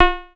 REDD PERC (34).wav